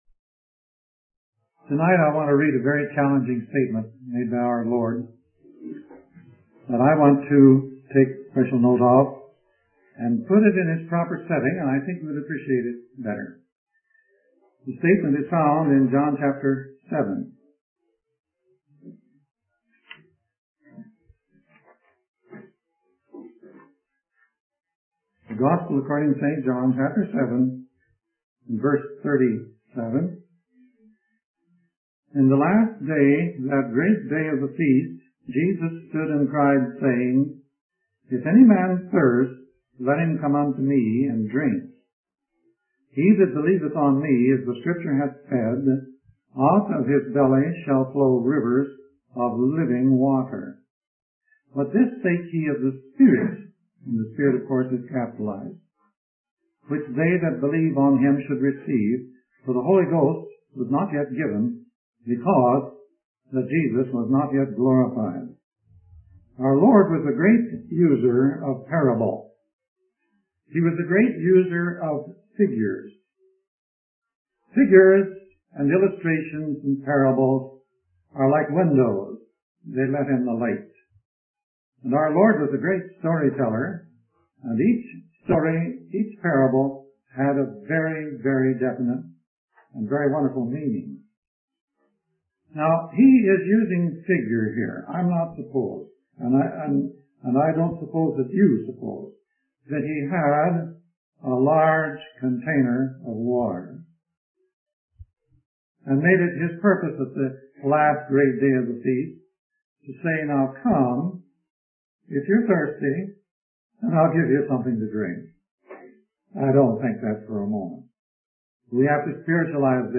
In this sermon, the preacher discusses the nature of pleasure and how it is divided into three parts: anticipation, realization, and reminiscence. He emphasizes that anticipation is the greatest part of pleasure.